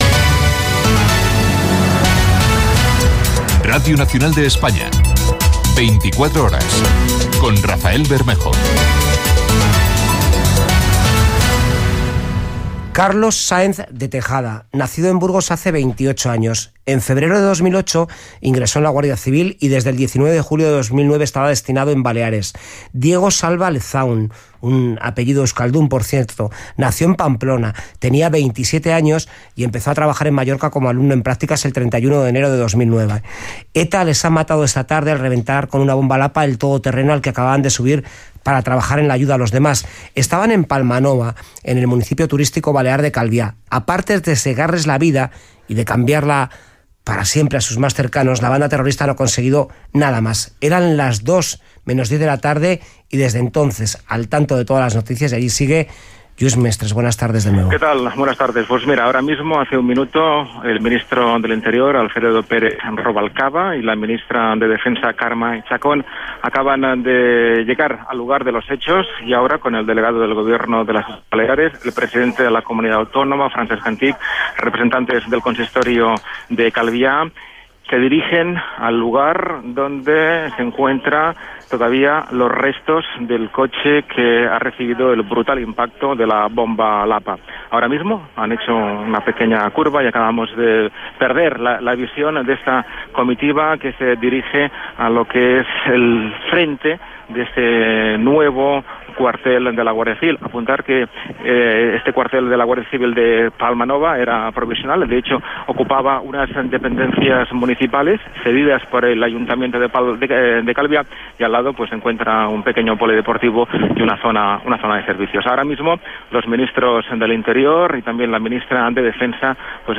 f7cd36431ce20a3cc77d9ff5062fed144bbc7cbb.mp3 Títol Radio Nacional de España Emissora Radio Nacional de España Barcelona Cadena RNE Titularitat Pública estatal Nom programa 24 horas Descripció Careta del programa, informació de l'atemptat d'ETA en un quarter de la Guardia Civil a Palma Nova (Calvià, Mallorca).
Gènere radiofònic Informatiu